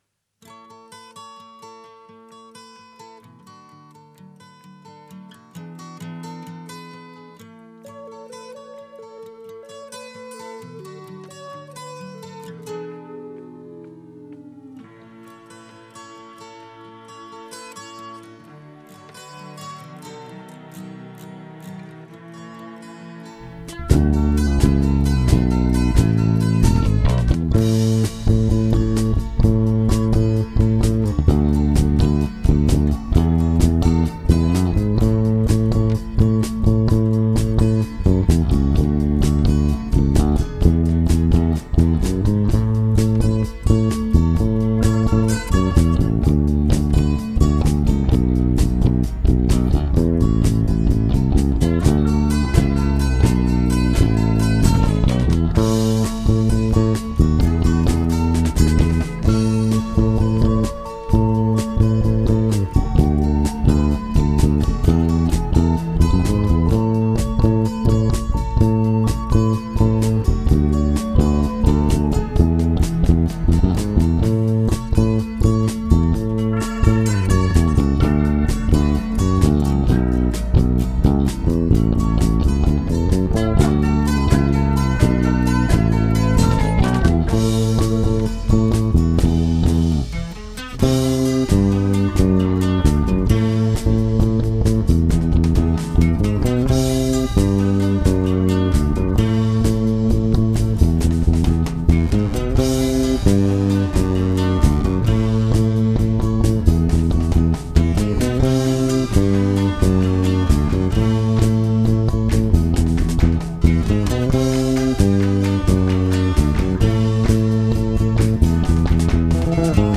instrumental
Cover / Bass only